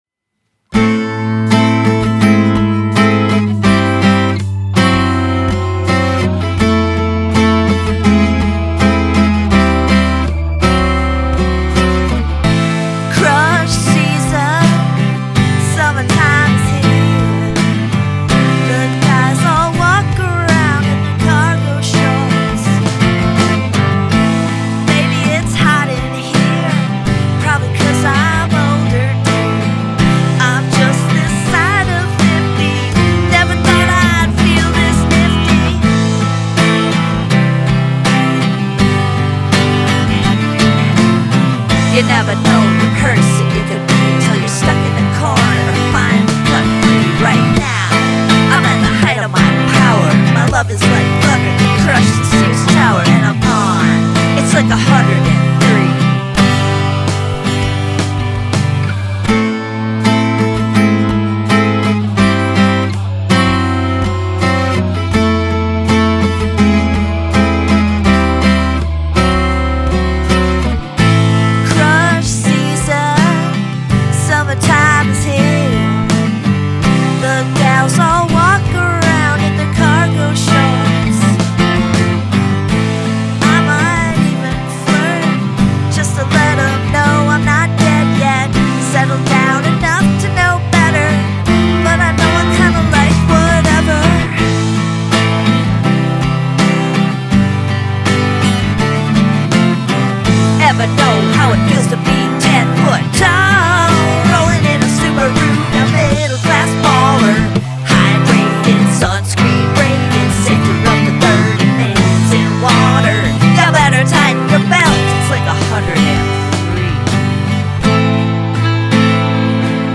The weird echoes make me happy.